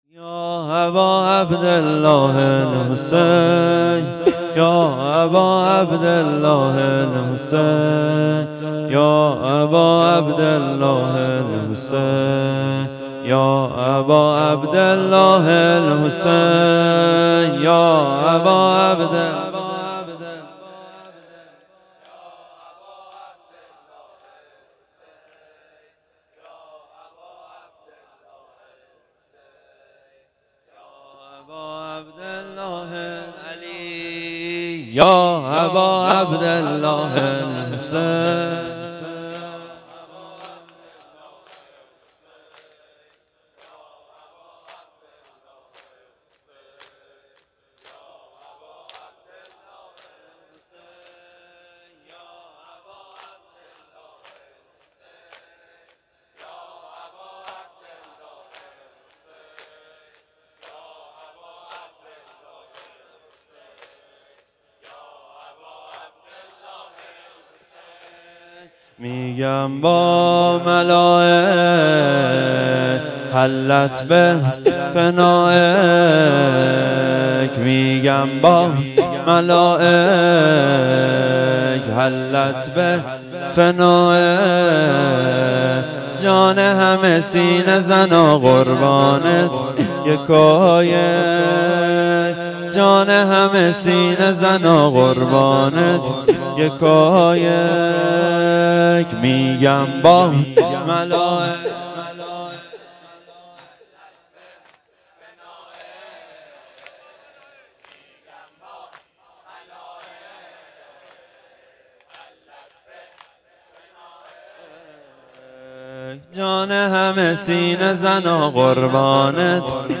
شب چهارم محرم ۱۴۴۴